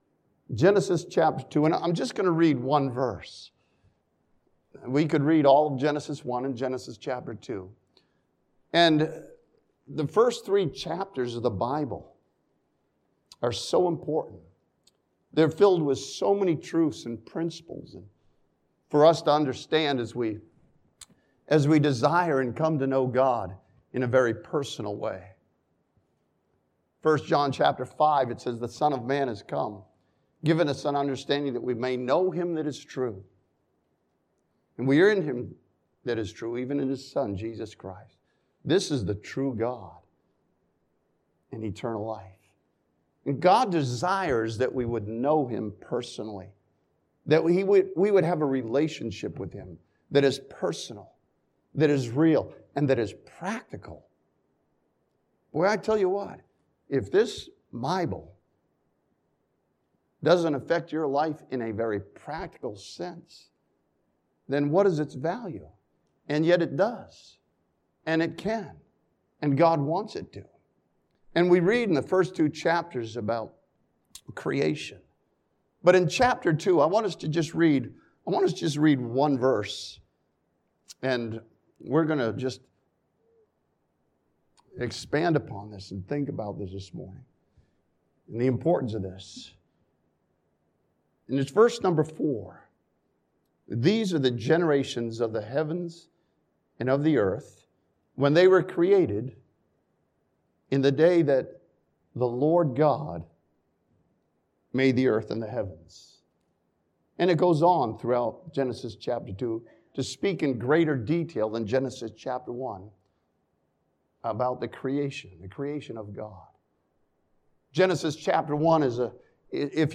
This sermon from Genesis chapter 2 takes a look at the greatness of God that has been shown to us.